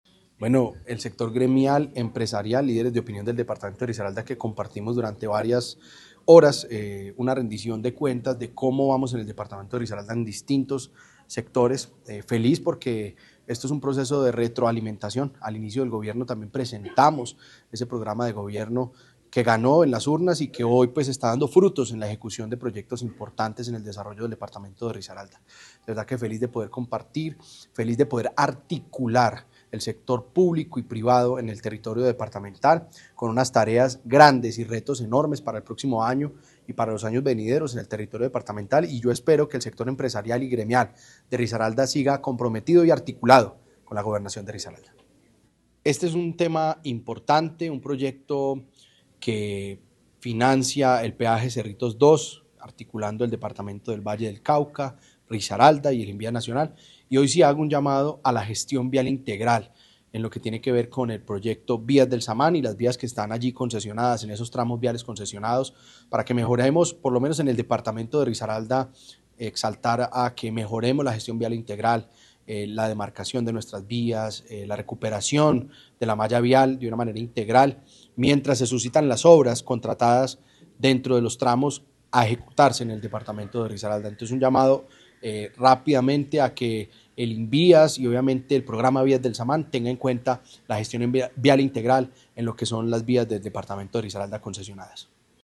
“Risaralda no solo está creciendo, estamos marcando el camino hacia el futuro”, afirmó el Gobernador frente a una audiencia que reconoció su liderazgo y gestión visionaria.
JUAN-DIEGO-PATINO-GOBERNADOR-RDA.mp3